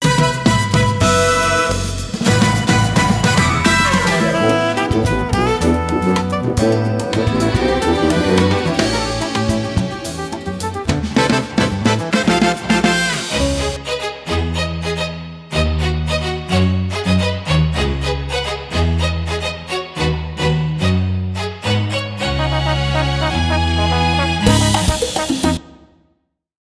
Latin JazzPercussionTrombone